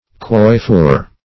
Quoiffure \Quoif"fure\ (kwoif"f[-u]r or koif"f[-u]r), n.